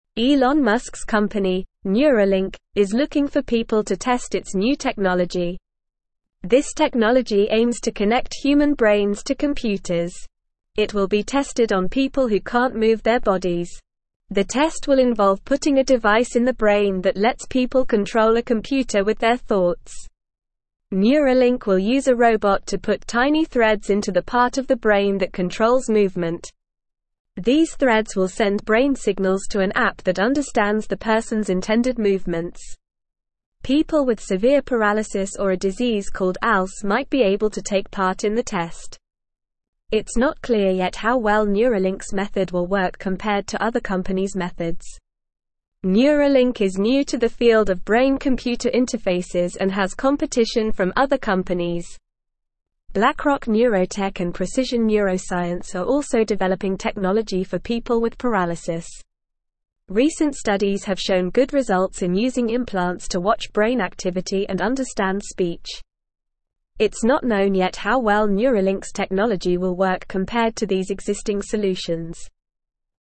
Normal
English-Newsroom-Lower-Intermediate-NORMAL-Reading-New-Brain-Tool-Help-People-Move-Computers-with-Thoughts.mp3